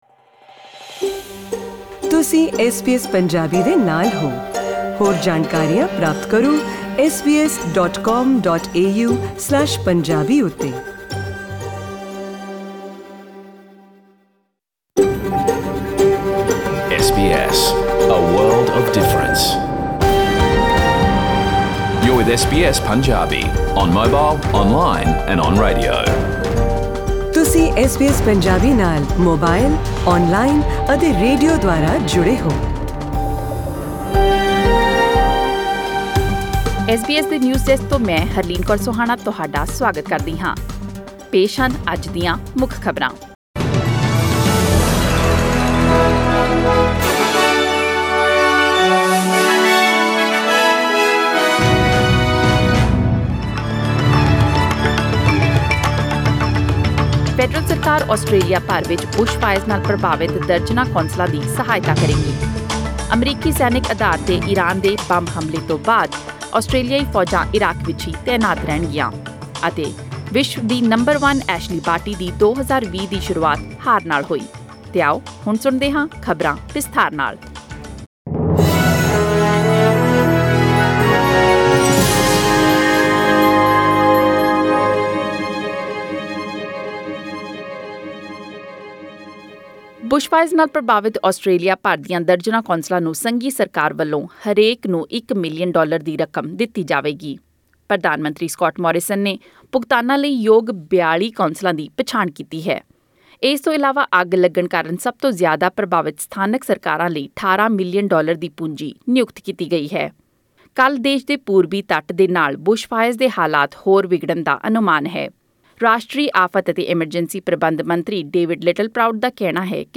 SBS Punjabi News: January 9, 2020